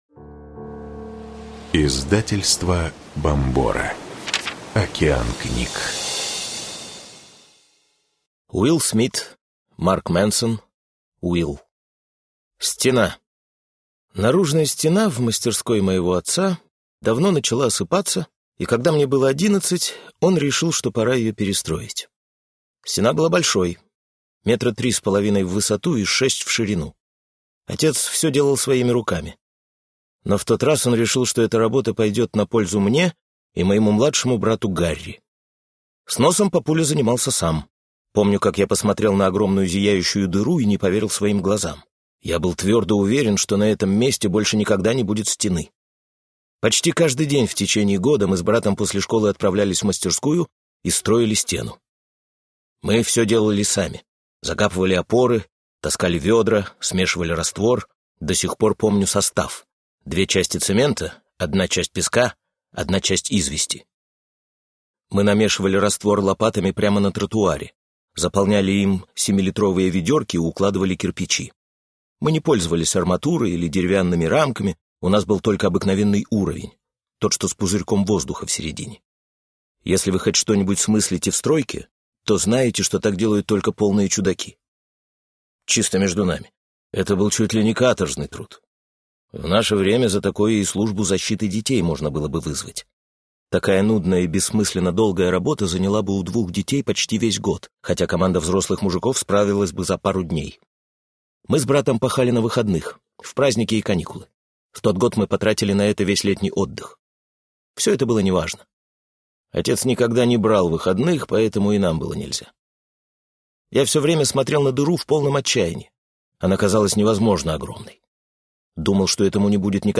Предзаказ на аудиоверсию автобиографии Уилла Смита «Will. Чему может научить нас простой парень, ставший самым высокооплачиваемым актером Голливуда» в озвучке актера Григория Сиятвинды доступен на сайте книжного сервиса